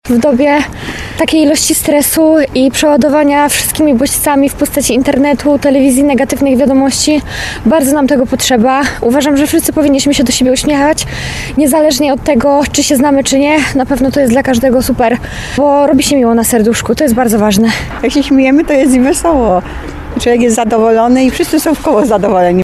W Światowy Dzień Uśmiechu pytamy tarnowian, dlaczego warto się uśmiechać.